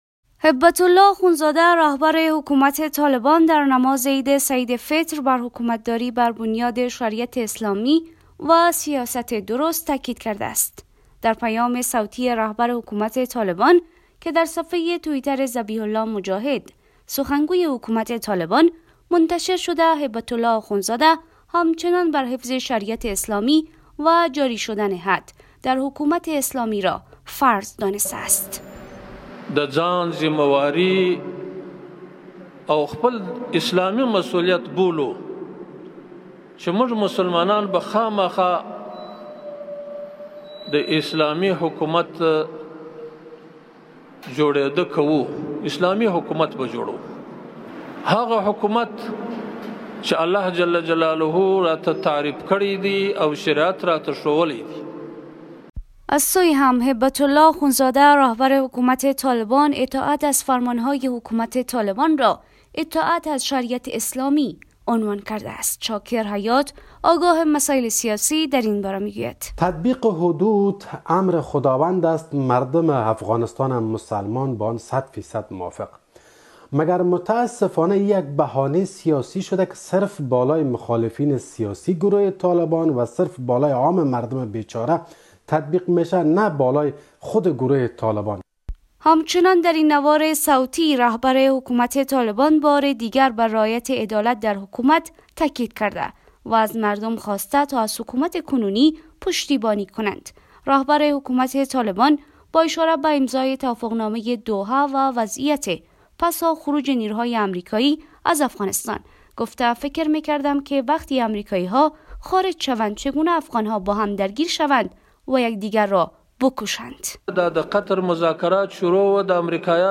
این اظهارات آخندزاده هنگام سخنرانی نماز عید سعید فطر در مسجد جامع قندهار ایراد شده است.